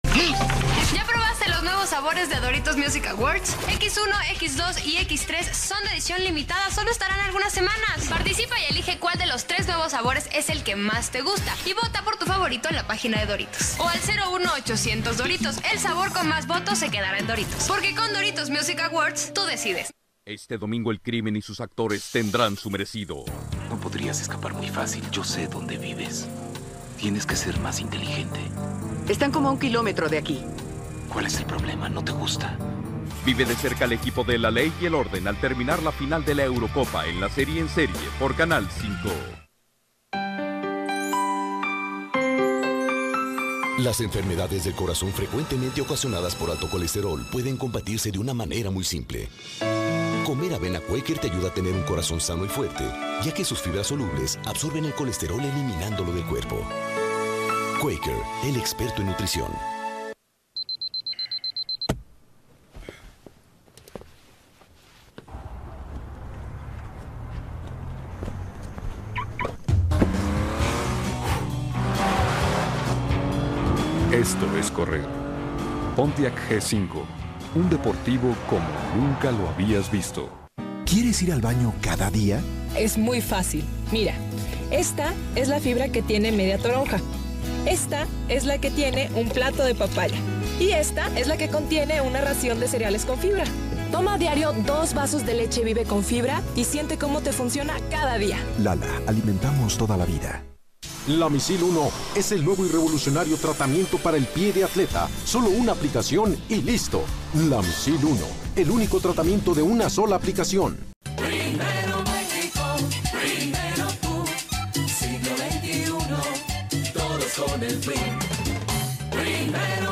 Bloque publicitario XHGC Canal 5